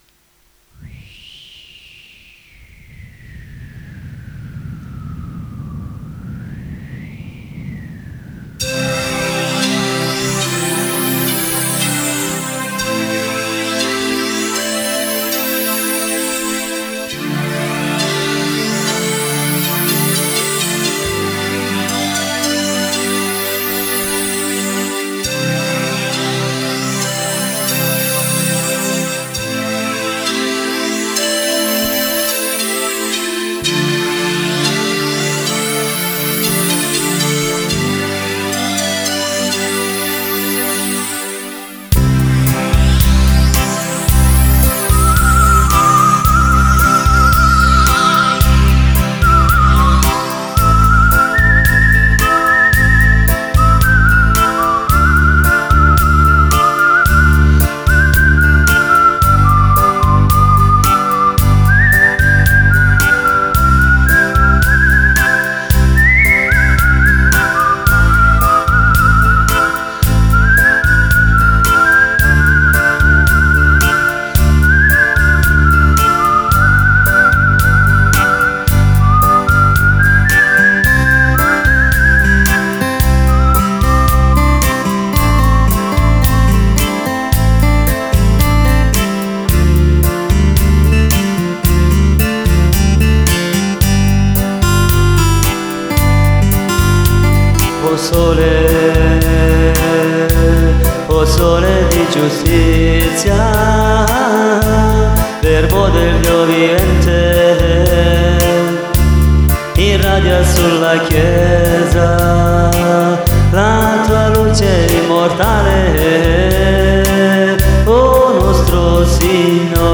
note di chitarra